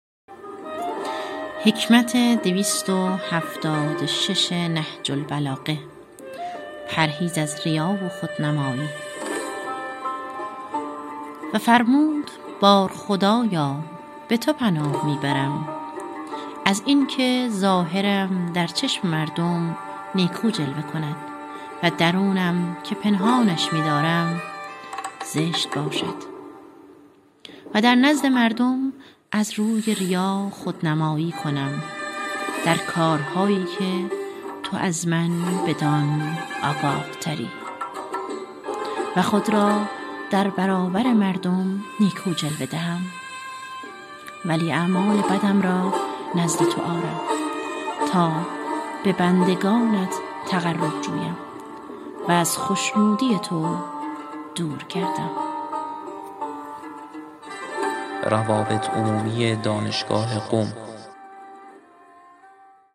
در جریان این پویش از دانشجویان، استادان و کارکنان خواسته شد که از هر نامه، کلام و خطبه‌های نهج‌البلاغه را که دوست دارند با صدا خود خوانده و ارسال کنند.